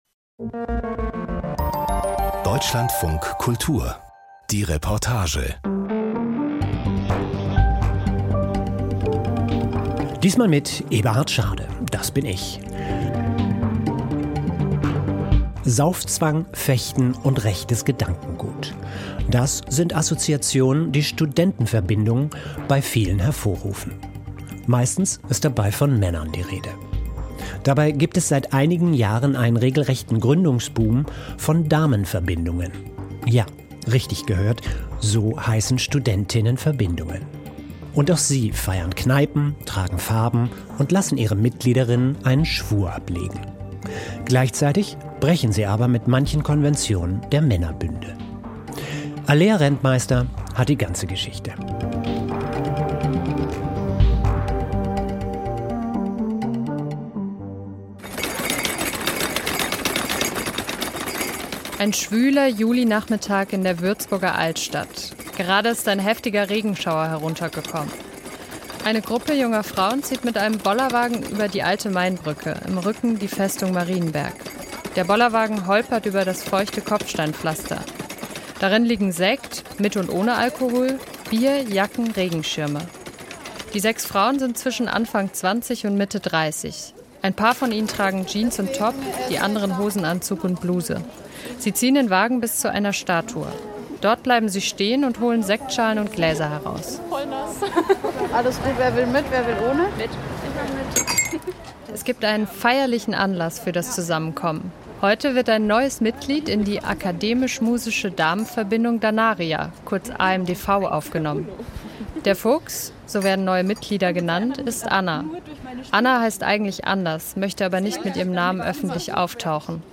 Gut recherchiert, persönlich erzählt – das ist die Reportage von Deutschlandfunk Kultur.